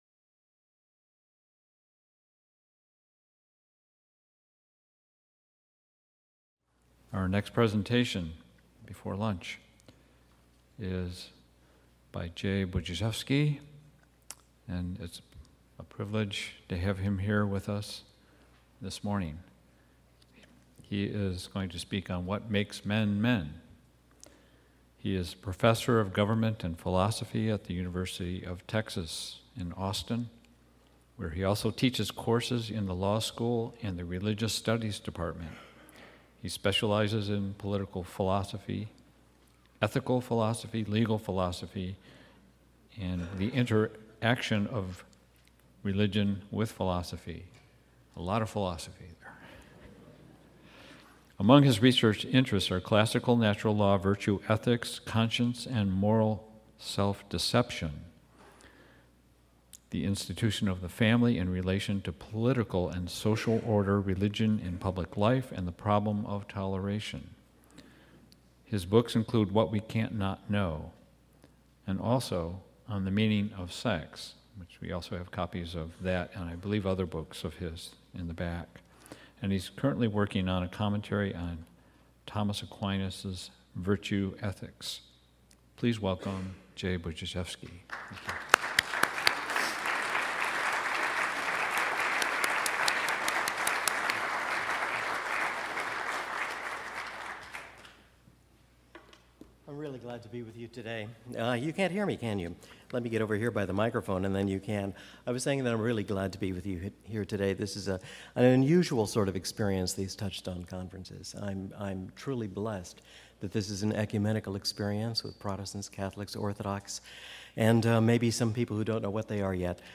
Duration: 41:45 with discussion afterwards — Talk delivered on Friday, October 12, 2018